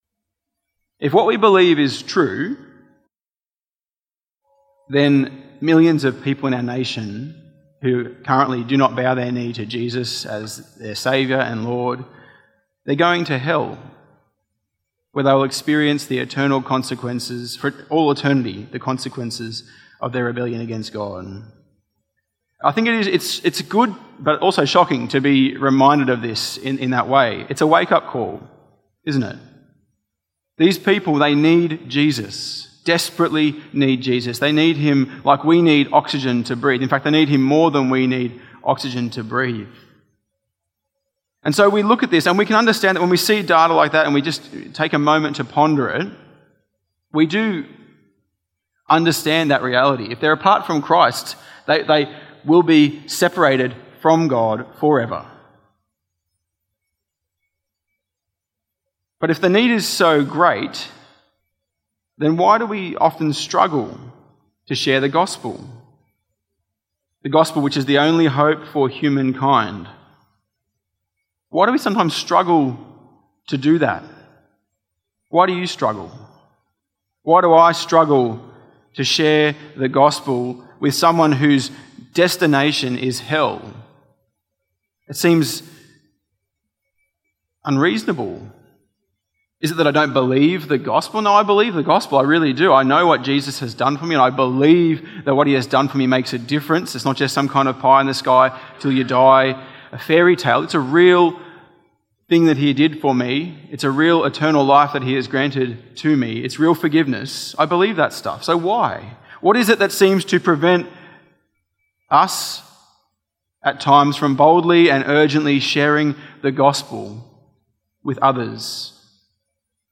Tagged with Sunday Evening